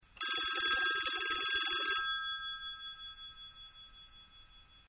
PIANETA GRATIS - Audio Suonerie - Telefoni e Fax - Pagina 10
phonering.mp3